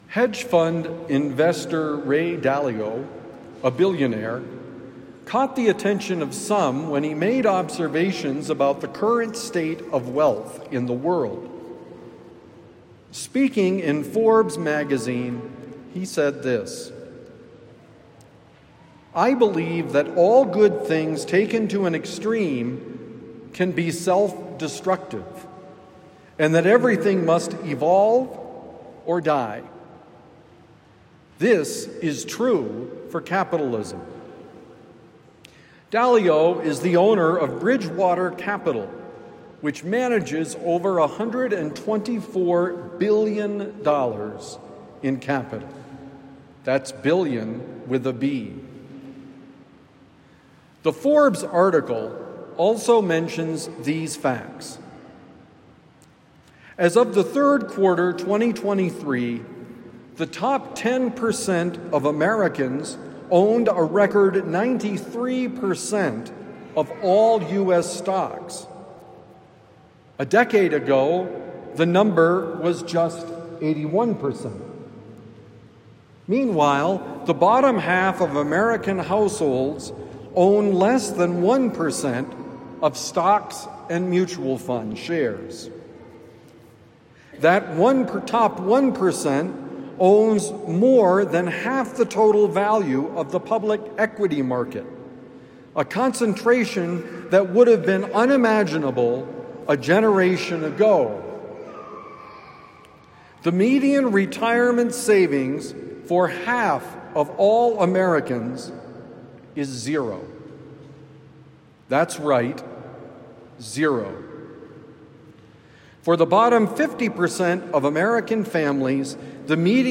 You can’t love God and love money: Homily for Sunday, September 21, 2025